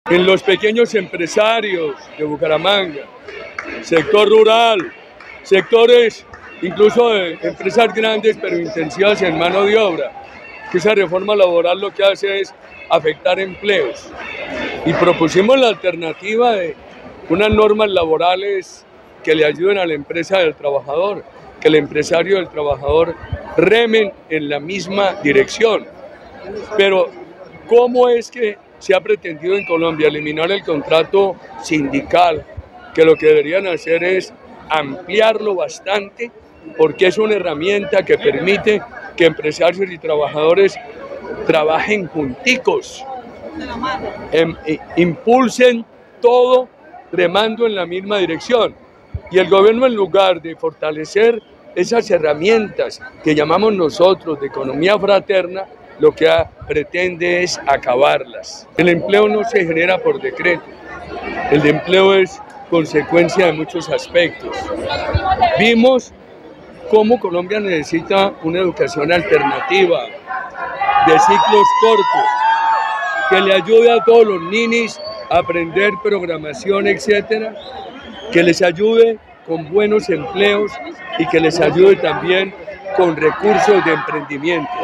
El empleo no se genera por decreto: Álvaro Uribe en el foro de precandidatos del Centro Democrático
Siendo la empleabilidad el eje temático del foro, el expresidente Álvaro Uribe Vélez, también dio a conocer su postura: “El empleo es la mejor política social. Más empleo implica menos pobreza”, dijo al dirigirse al público presente.